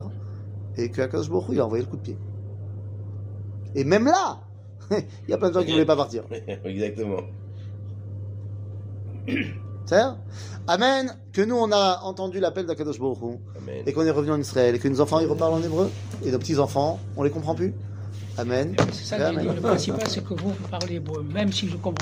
שיעור מ 03 ינואר 2023